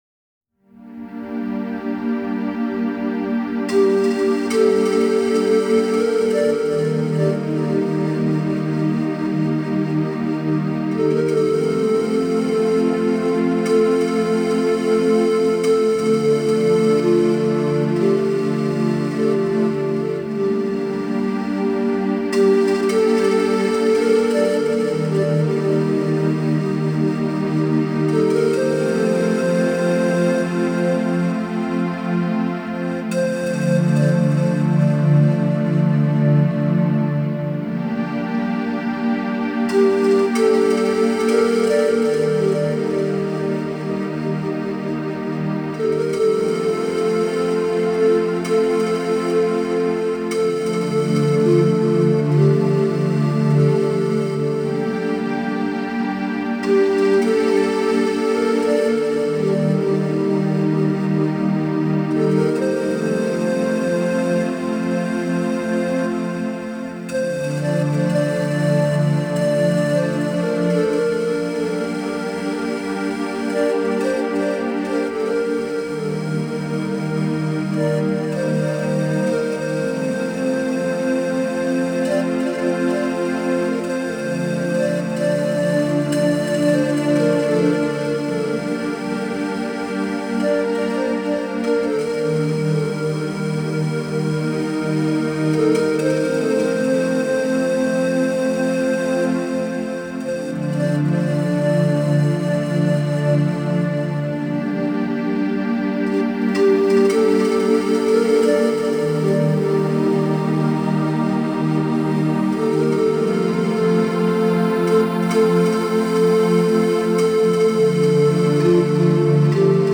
Исцеляющая музыка -Флейта - Разное - Разное - Каталог файлов - ФОТО|лучшее о фотографии-Fotozond